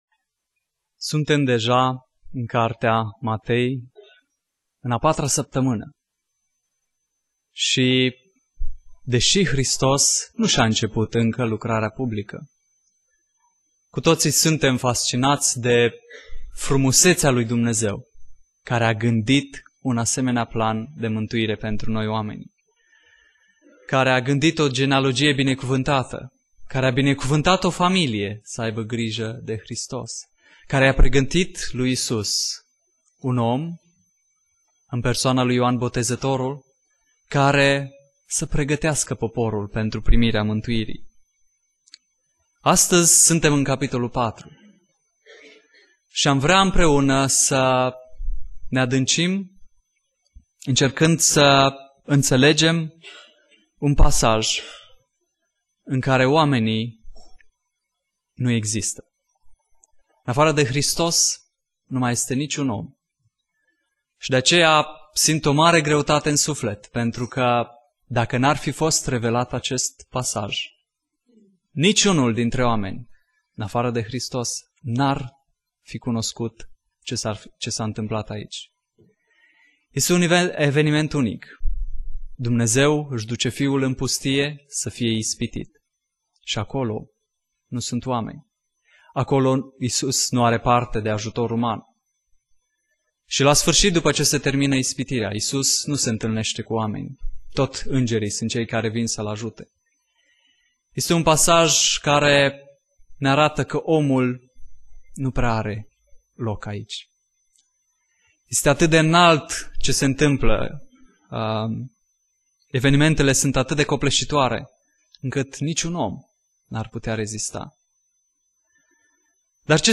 Predica Exegeza - Matei 4